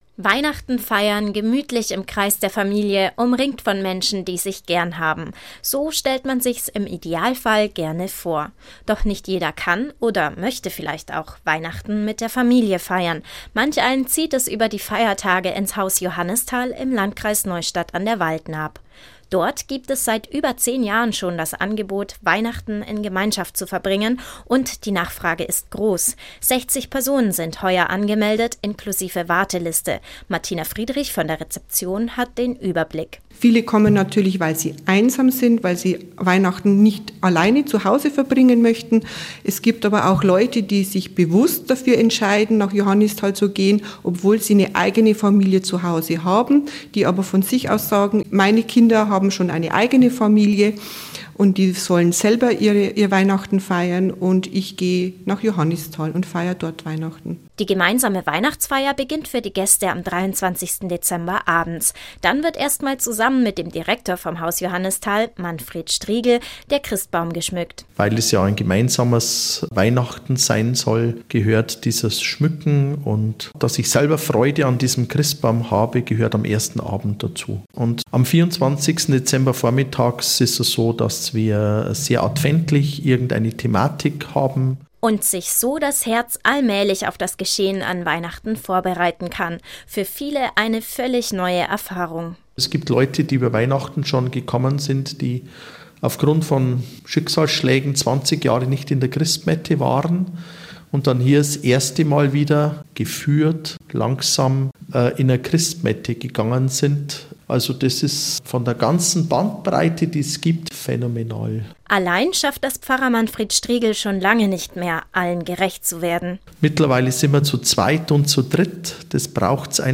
Radio-Interview